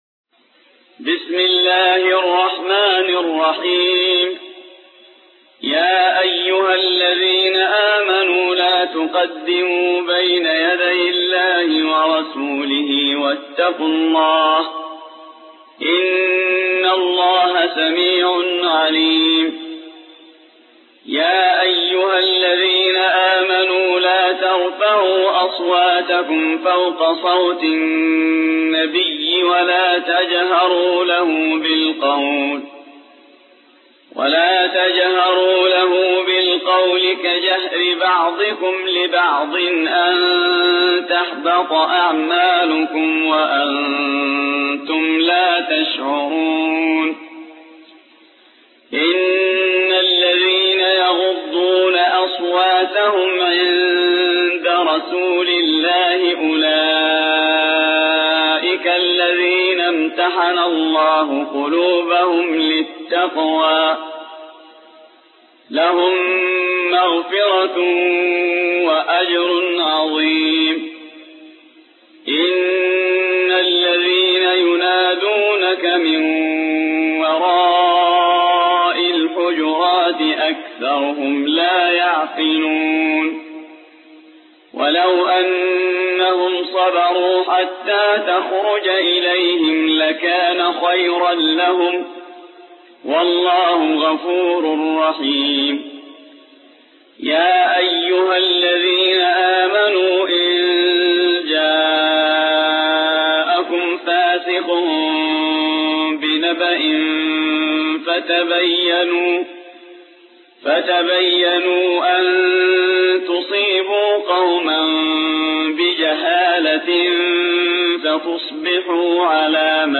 49. سورة الحجرات / القارئ